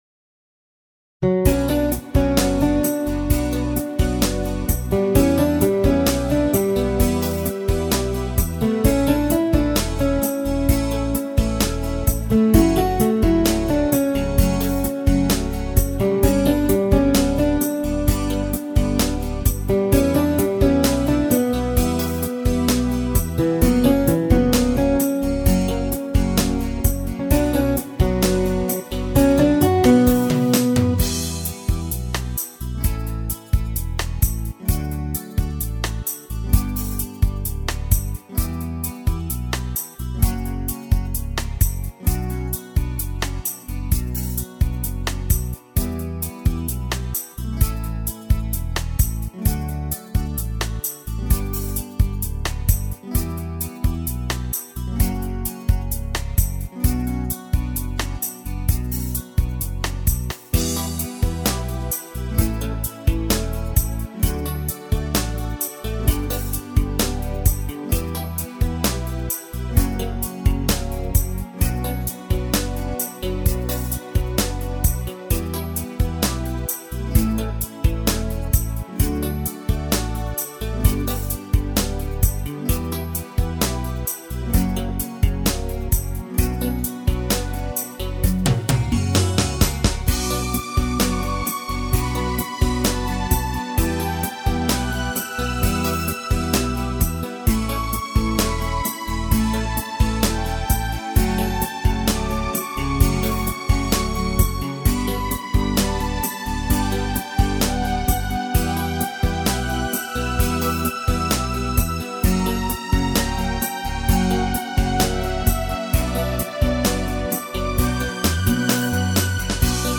минусовка версия 17903